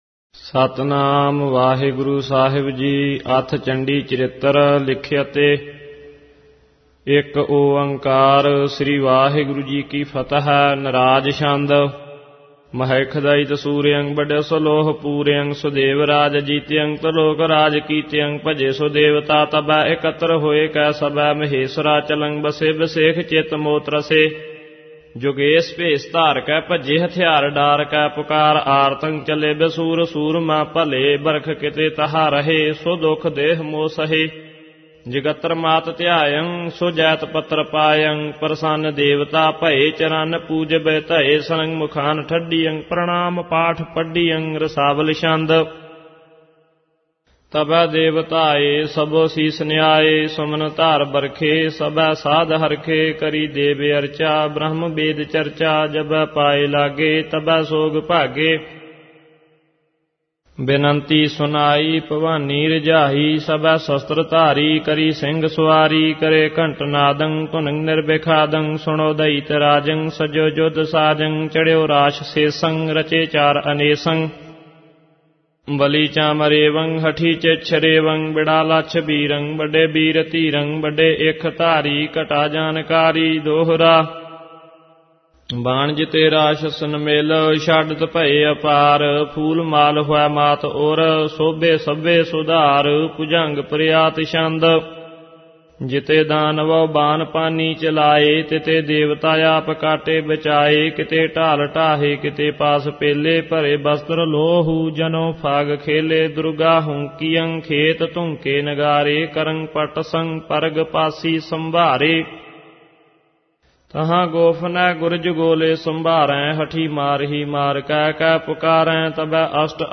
Album:Chandi.Charittar Genre: -Gurbani Ucharan Album Info